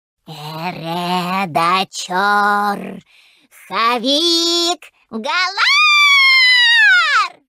.Обладает очень писклявым и довольно забавным голосом, который мог бы веселить окружающих, если бы не суровый нрав, неоспоримый авторитет и огромная обидчивость его хозяйки.